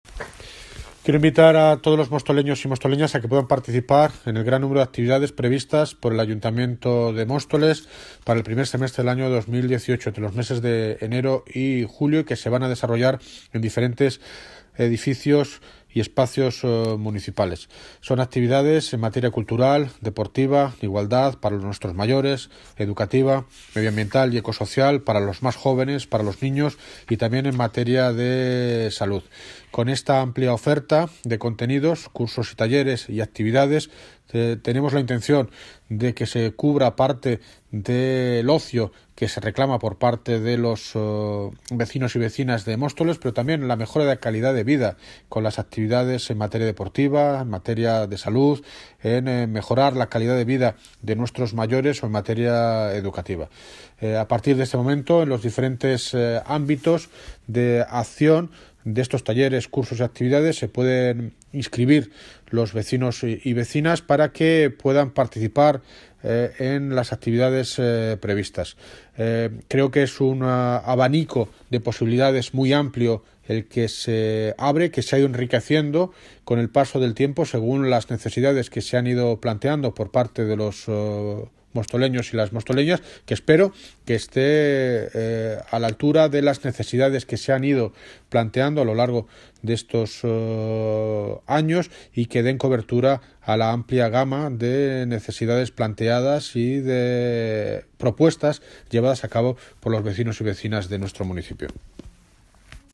Sonido - David Lucas (Alcalde de Móstoles) Sobre Guía de Actividades 2018